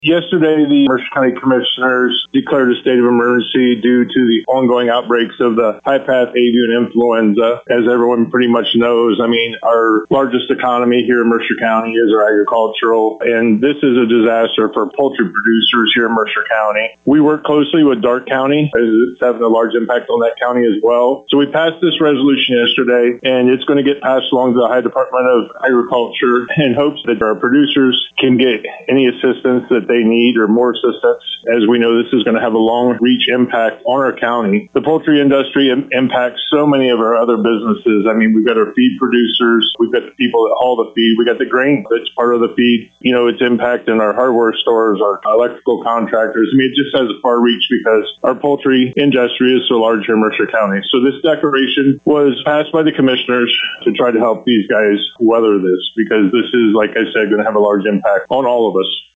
To hear comments from Mercer County Commissioner Brian Miller: